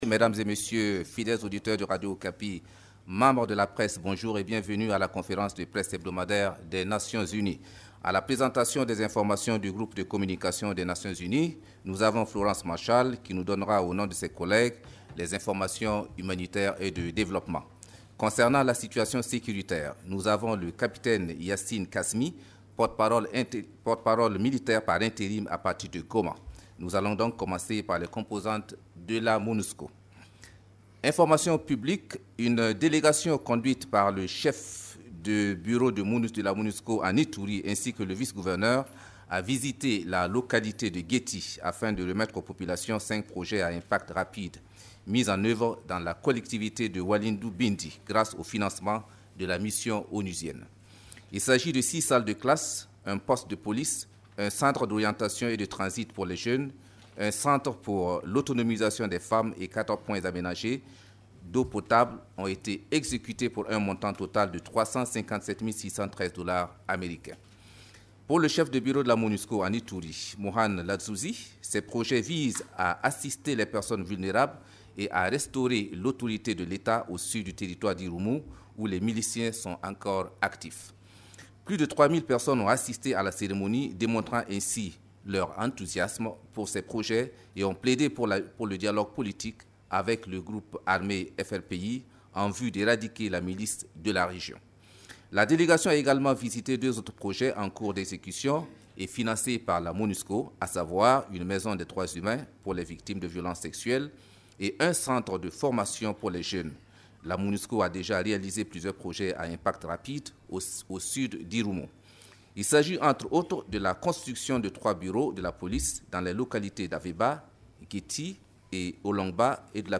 Conférence de presse du 10 août 2016
Vous pouvez écouter la première partie de la conférence de presse:
premiere_partie_de_la_conference_de_presse_de_la_monusco-web.mp3